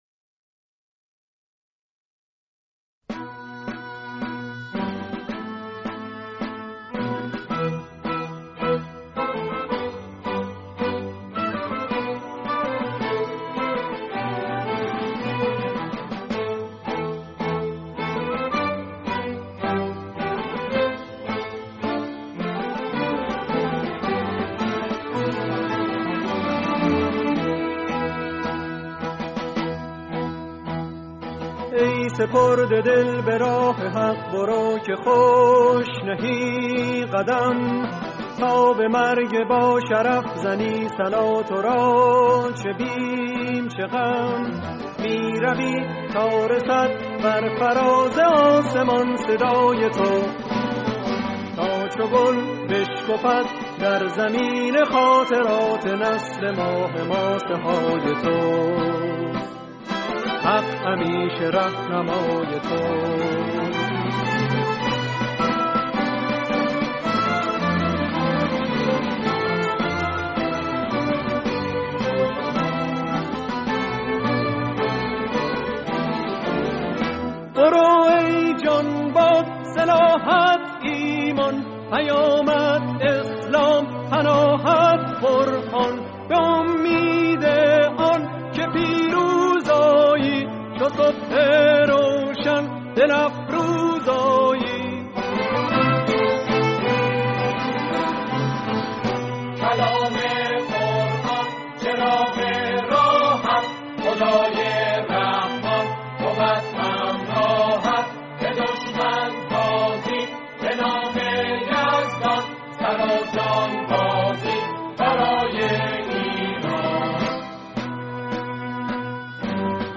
سرودی
گروه کر